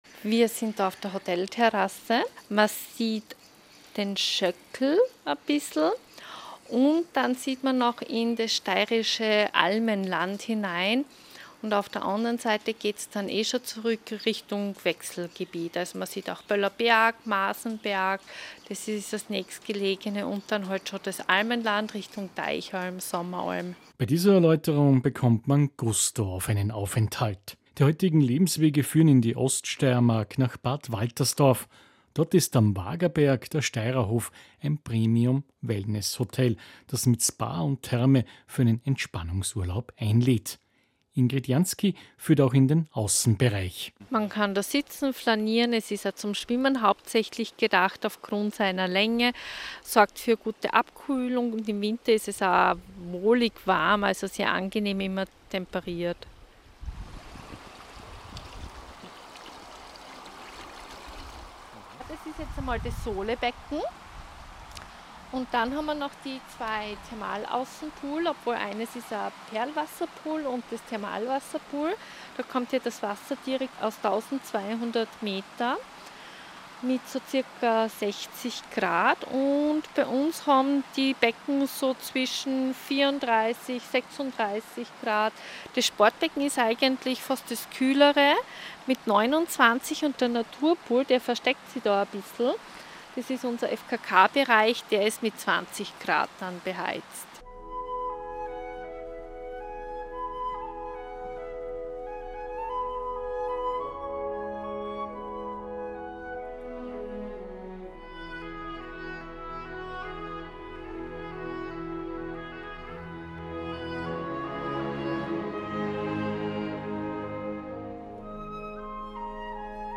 Interview_ Radio Stephansdom